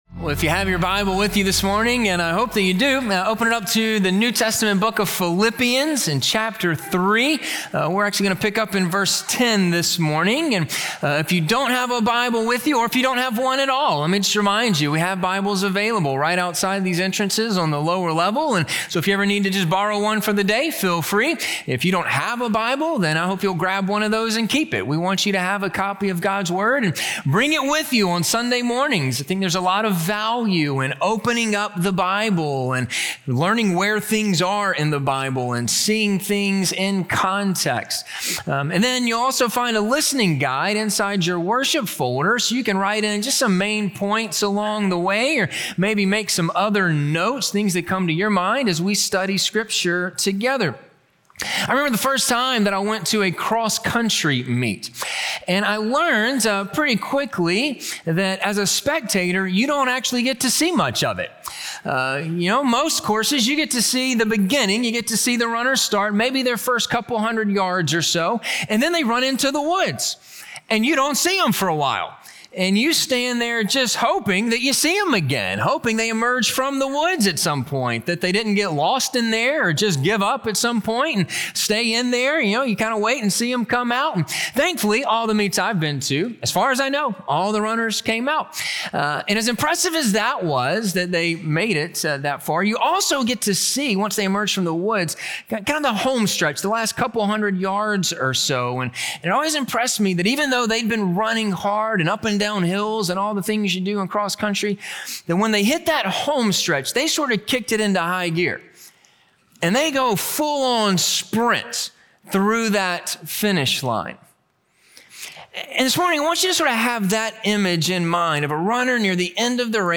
Press On! - Sermon - Ingleside Baptist Church